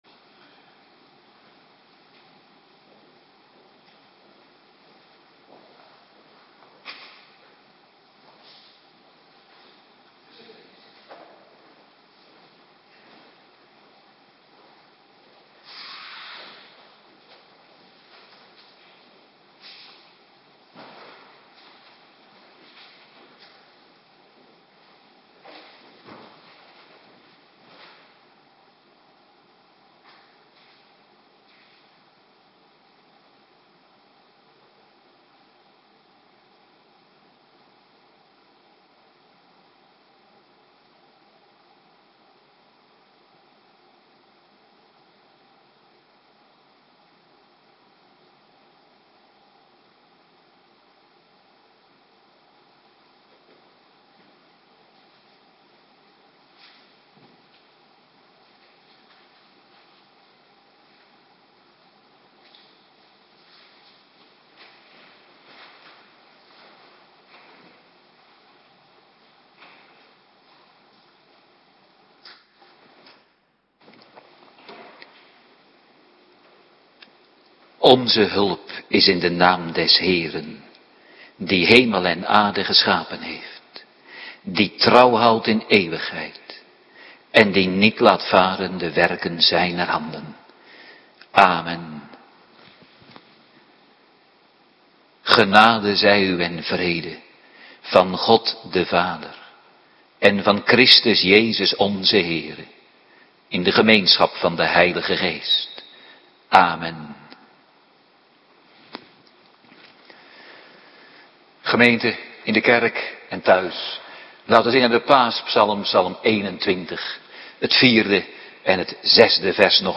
Avonddienst
18:30 t/m 20:00 Locatie: Hervormde Gemeente Waarder Agenda